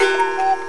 1 channel
CUCKOO.mp3